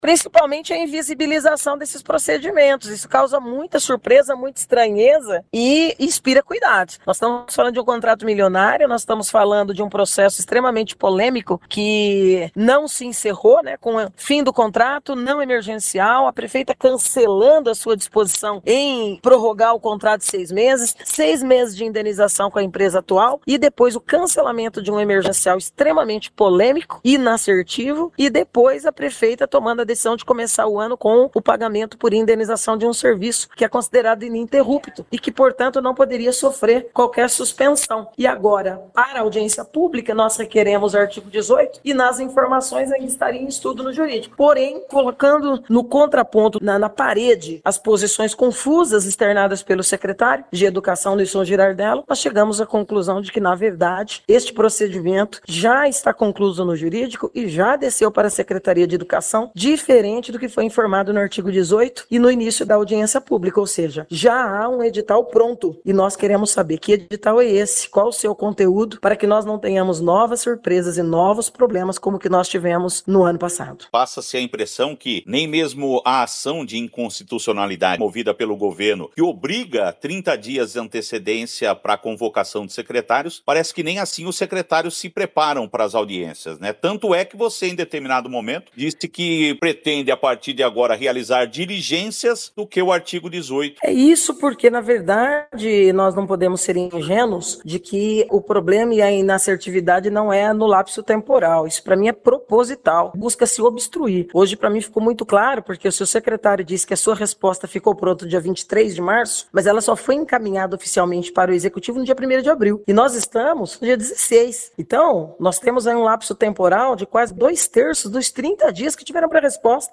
A situação do transporte escolar também foi debatida durante a audiência, mostrando que o prazo de 30 dias de convocação de secretários para a realização de audiências públicas, não vem sendo suficiente para que os gestores das pastas municipais respondam aos questionamentos feitos pelos parlamentares. Estela Almagro falou sobre essa situação.